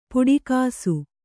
♪ puḍi kāsu